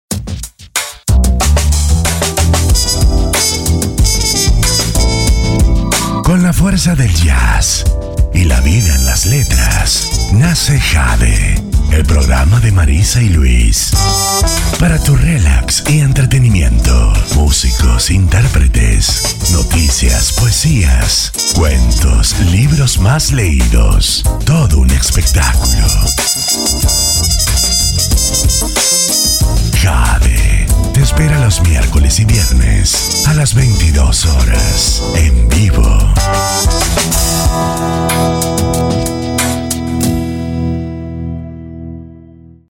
Masculino
Espanhol - América Latina Neutro